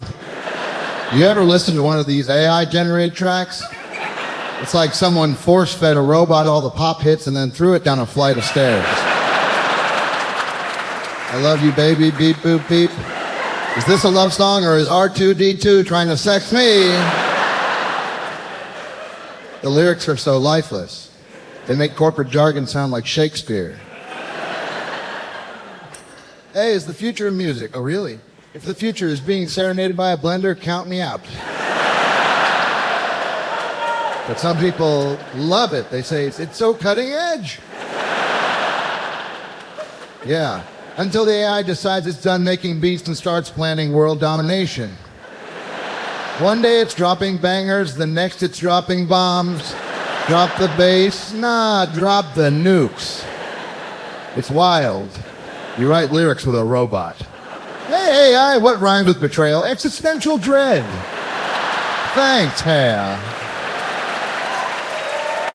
Not music, but some AI stand up about AI generated music… :slight_smile: